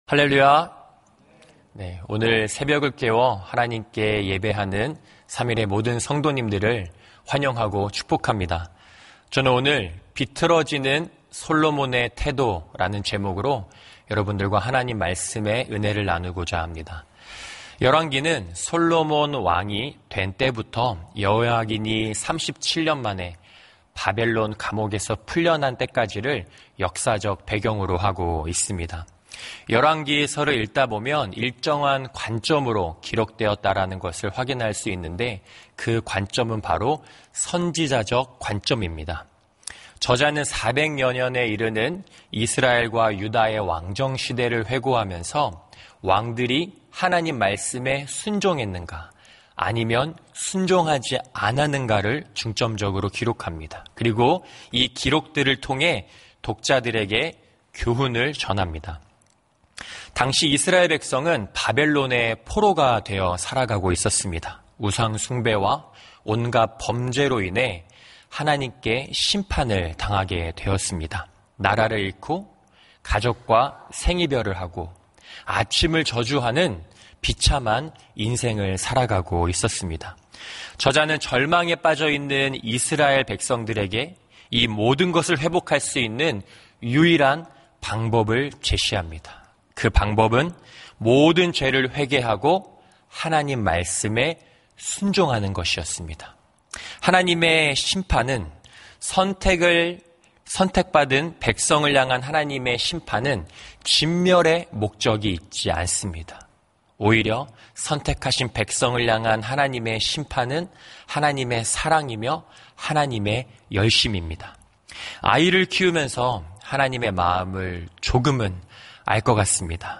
예배 새벽예배